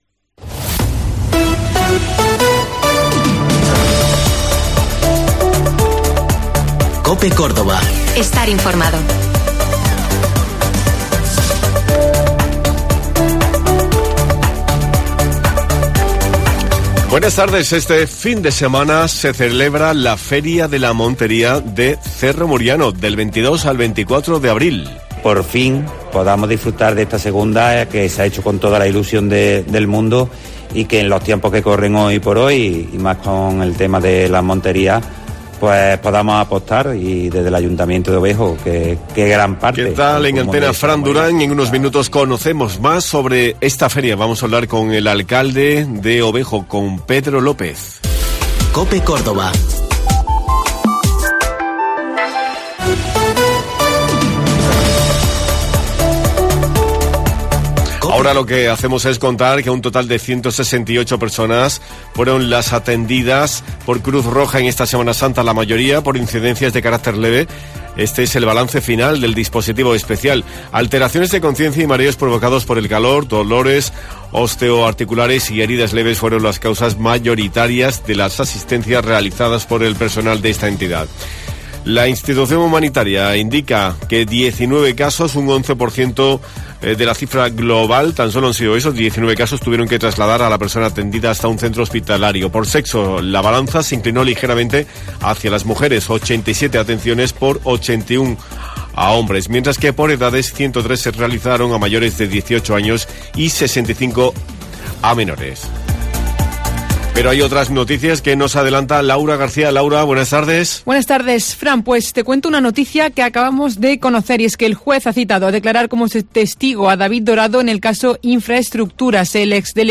Nos hemos acercado por Obejo porque este fin de semana celebra la II Feria de la Montería de Cerro Muriano. Del 22 al 24 de abril los amantes de la caza tienen una cita en el Polideportivo de Cerro Muriano. Hemos hablado con el alcalde de Obejo, Pedro López, para conocer de cerca cuál es su oferta.